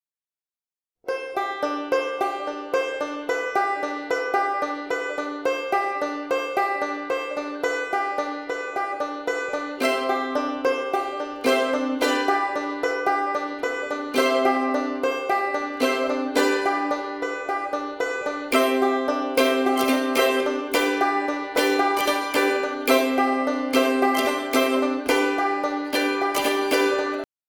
In the basement working on the new song tonight.
Here’s a short prelim clip of the intro. Just banjo and mandolin so far.